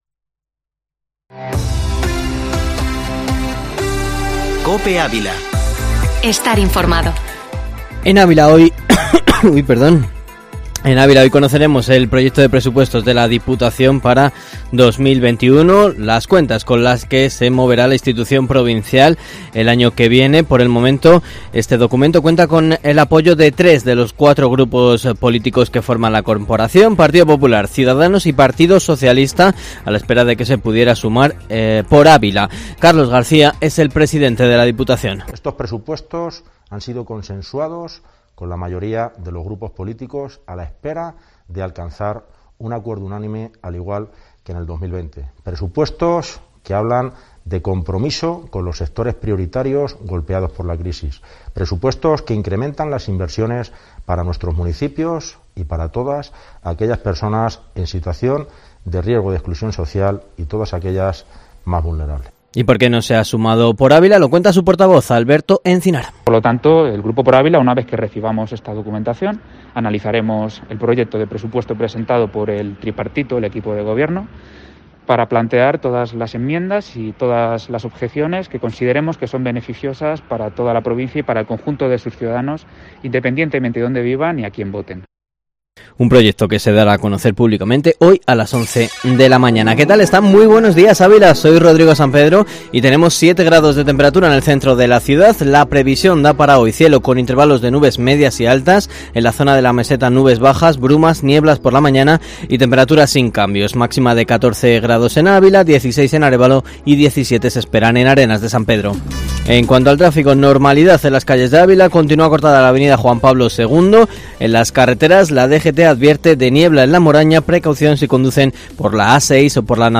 Informativo matinal Herrera en COPE Ávila 12/11/2020